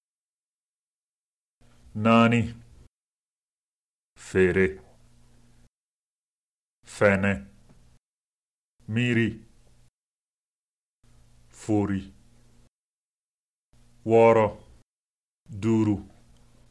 En Bambara, toutes les voyelles sont susceptibles d’être utilisées aussi comme voyelles longues.
VOYELLES LONGUES
01a-04-voyelles_longues.mp3